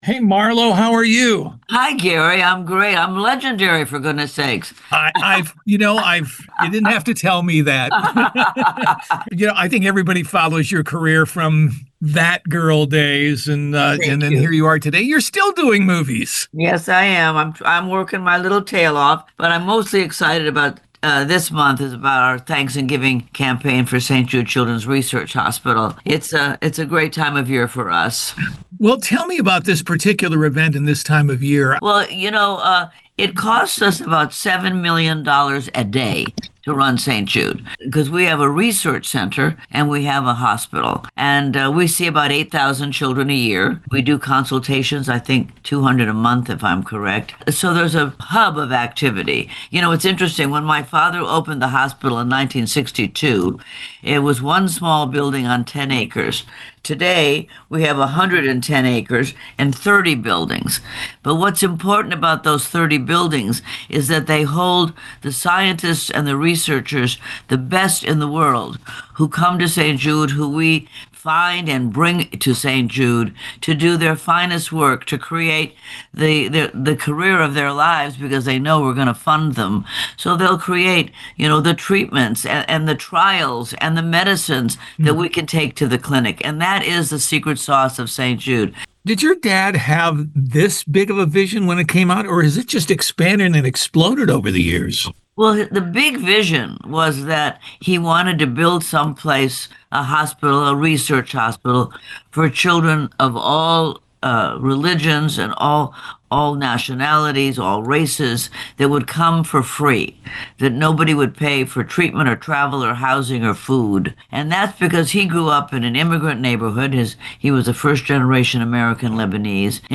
RADIO EDIT  4 MINUTE INTERVIEW
marlothomas_mixdownradioedit.mp3